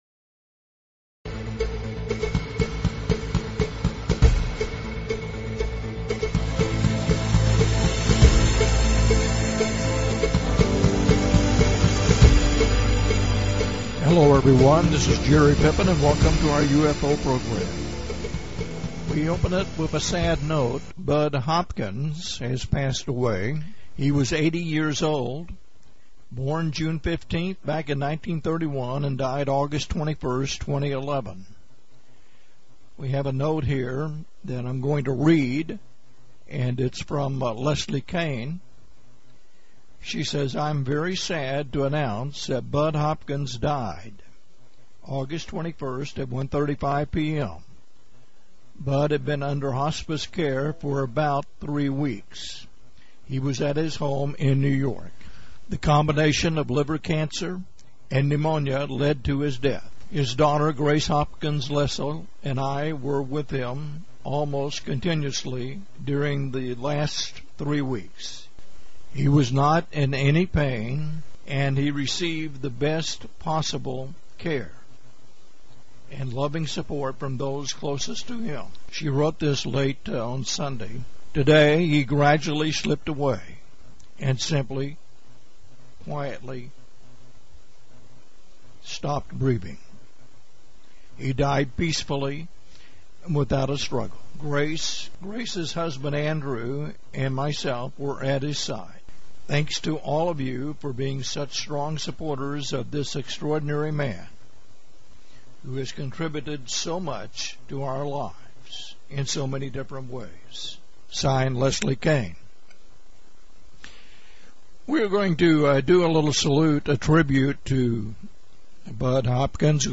The Crash occurred in 1963 and involved three dead ETs. (This interview was conducted in November of 2003) Windows Media Version For the mp3 podcast version download the Tribute Show described above